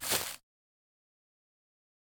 footsteps-single-outdoors-002-00.ogg